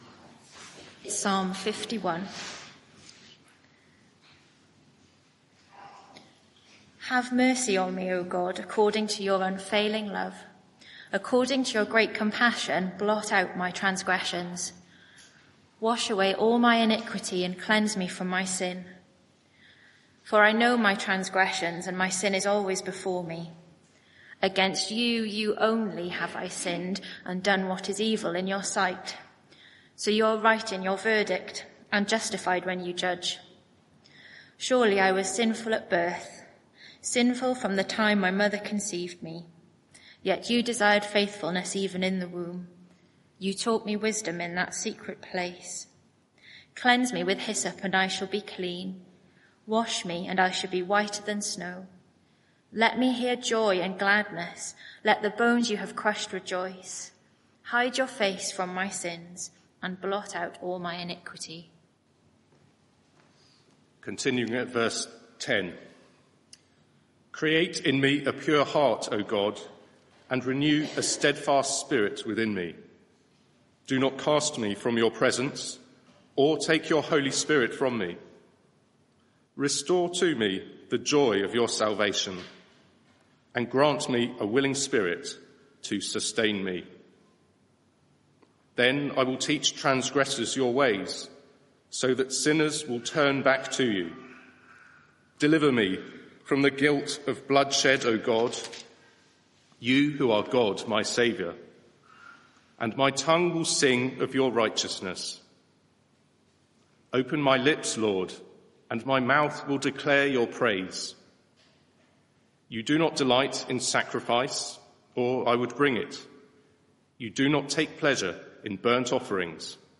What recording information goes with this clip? Media for 11am Service on Sun 11th May 2025 11:00 Speaker